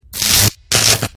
Electric power - Электрический разряд
Отличного качества, без посторонних шумов.
507_electric-power.mp3